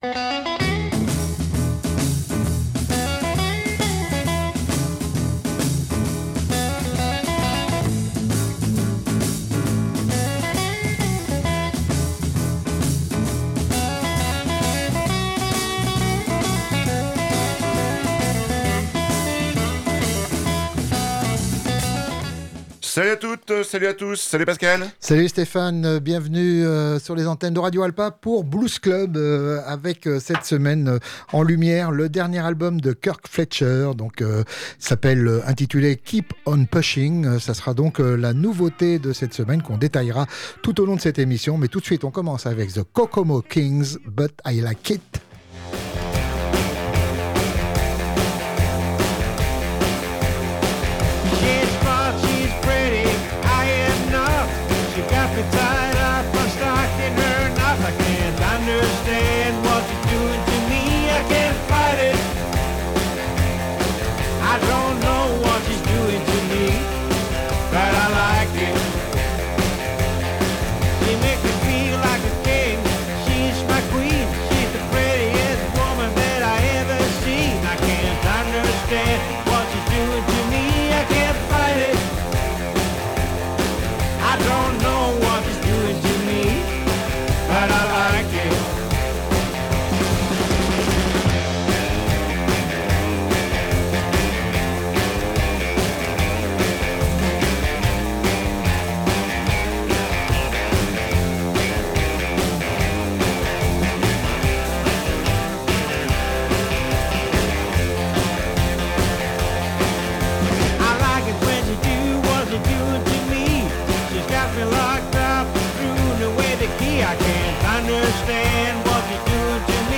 un album pour les fans de blues